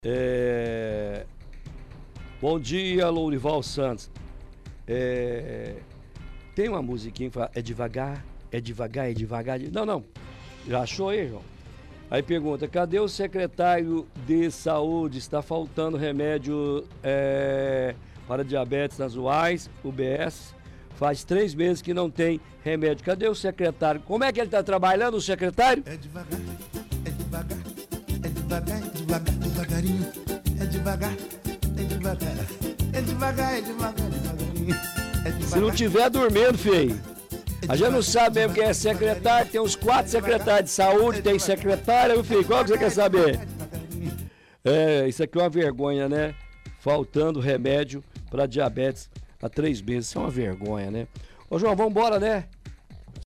Música “é devagar”.